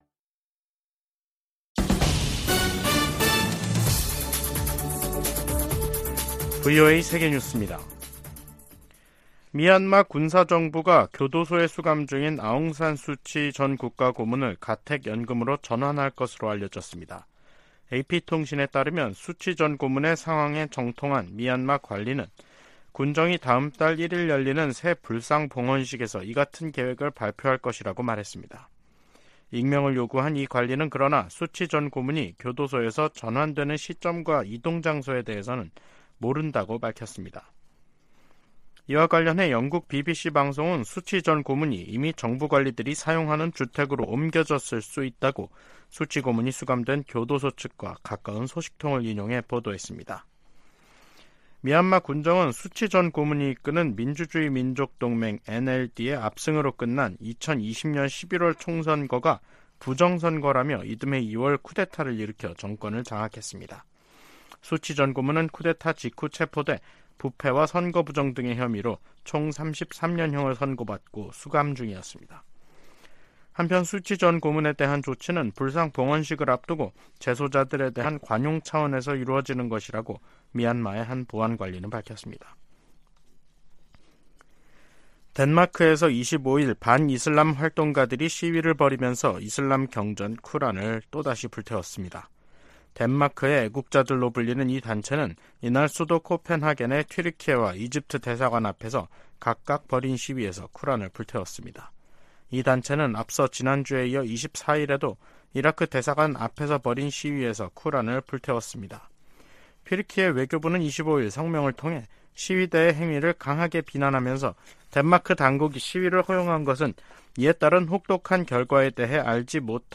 VOA 한국어 간판 뉴스 프로그램 '뉴스 투데이', 2023년 7월 26일 3부 방송입니다. 북한의 '전승절' 행사에 중국과 러시아 대표단이 참가하면서 북중러 3각 밀착이 선명해지는 것으로 분석되고 있습니다. 미 국무부는 중국과 러시아가 북한의 불법 활동을 자제하는 역할을 해야 한다고 강조했습니다. 미 국방부는 월북한 미군 병사와 관련해 아직 북한 측의 응답이 없다고 밝혔습니다.